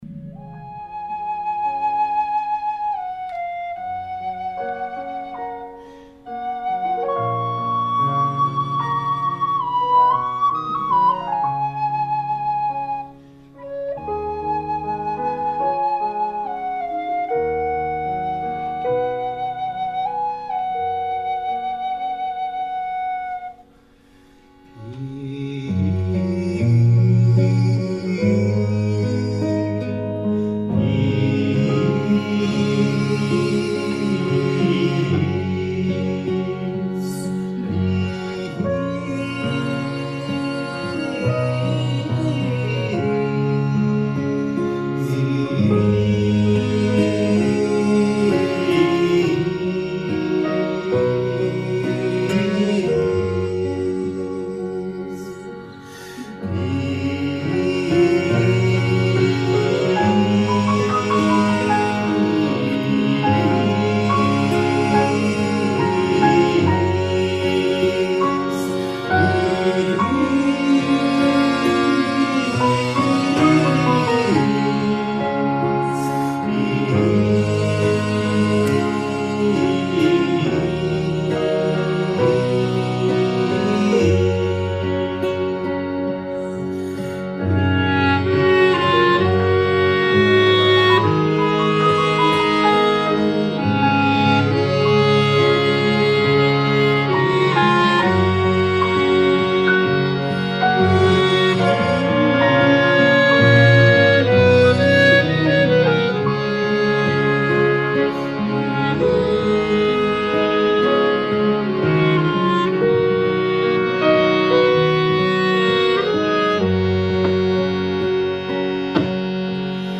(elhangzott: 2023. július 5-én, szerdán délután hat órától élőben)